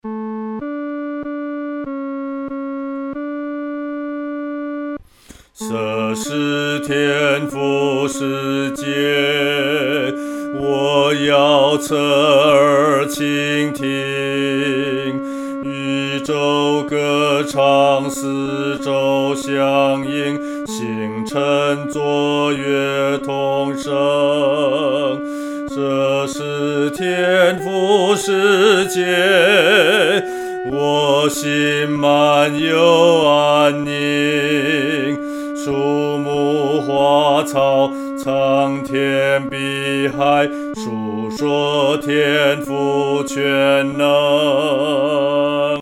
合唱
女低